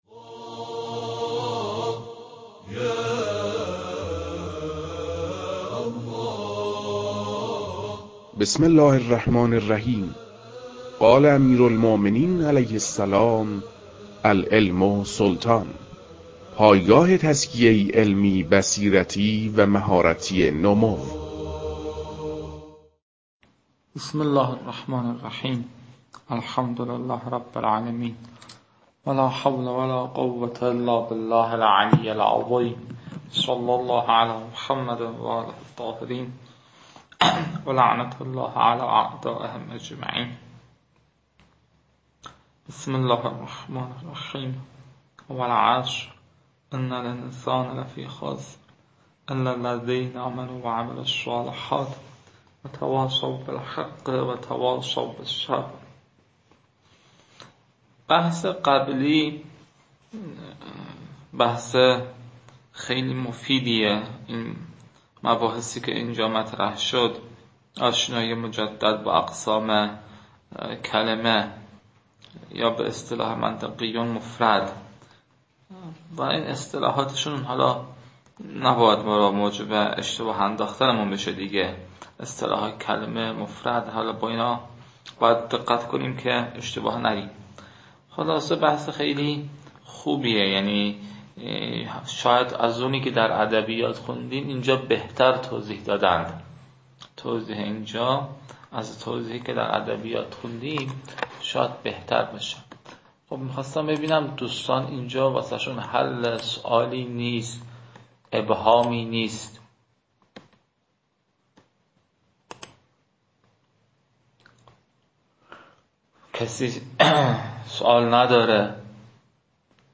در این بخش، کتاب «منطق مظفر» که اولین کتاب در مرحلۀ شناخت علم منطق است، به صورت ترتیب مباحث کتاب، تدریس می‌شود.